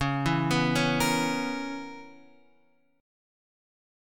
C# Minor 13th